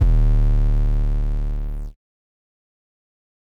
Metro 808 10.wav